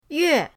yue4.mp3